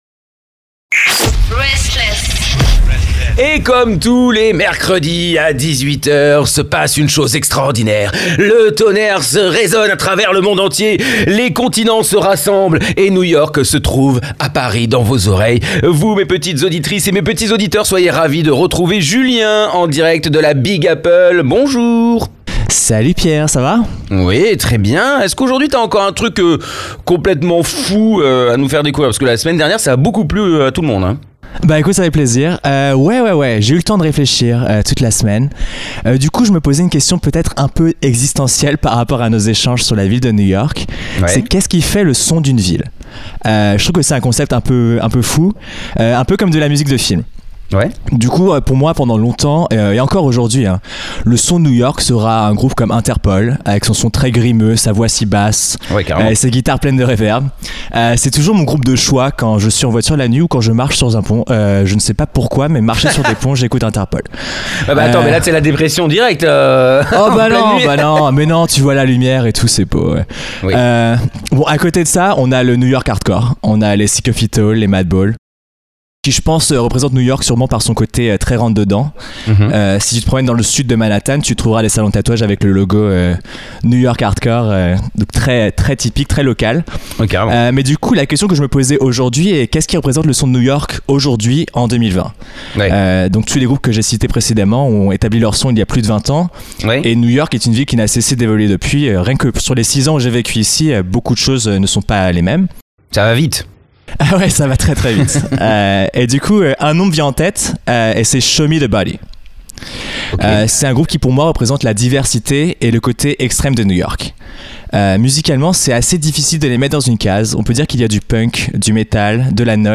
Mais imagine un banjo plein de feedback et de distorsion, et une basse d’une violence absolue.